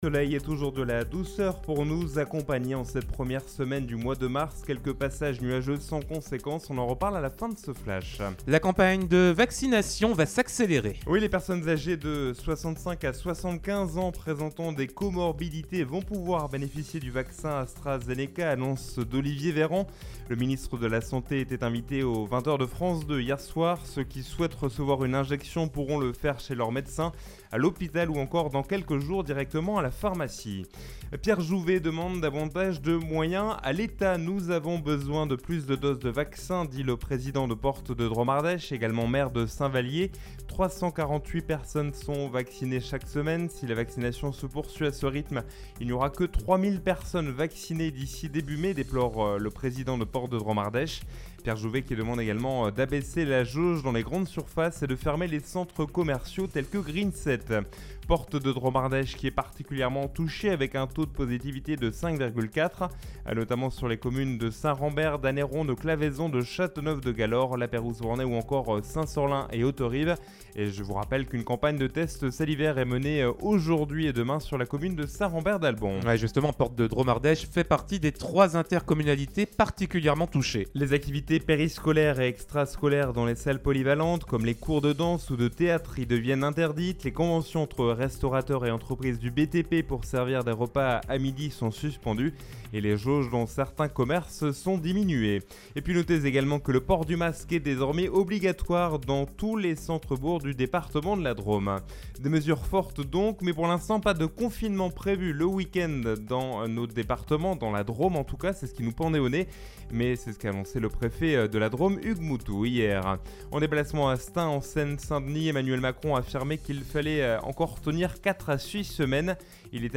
in Actualités, Journal du Jour - Flash